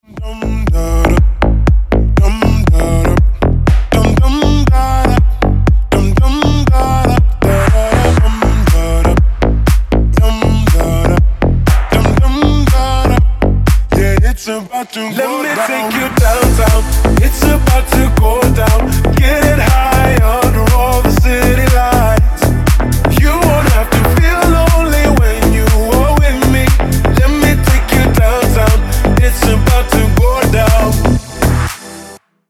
мужской вокал
slap house